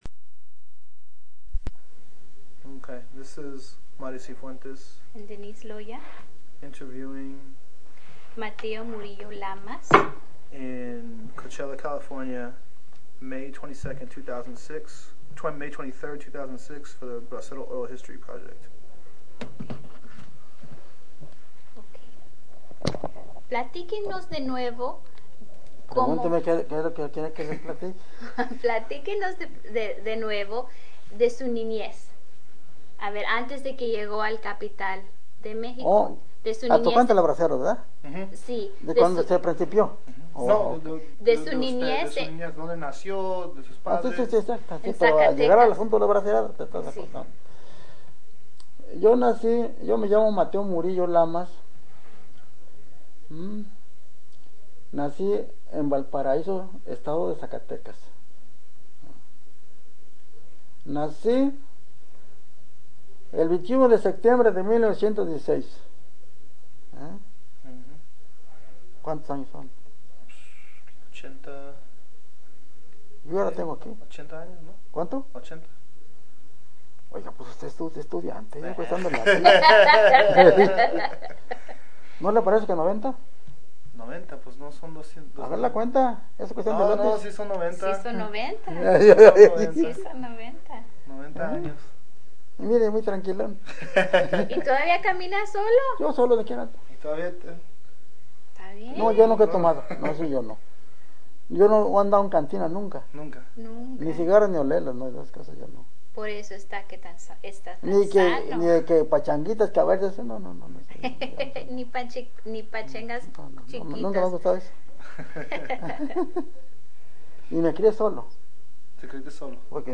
Summary of Interview: